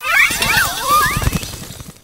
espathra_ambient.ogg